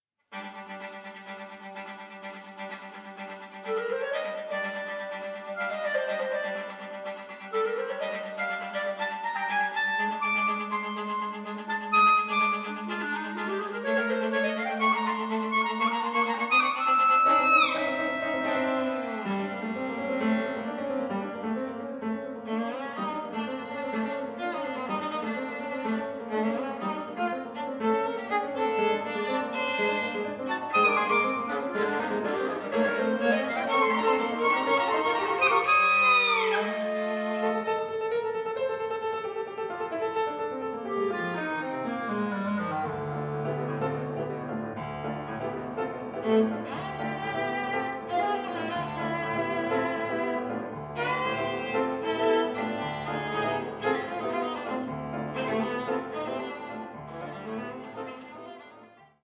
Stereo
clarinet
viola
piano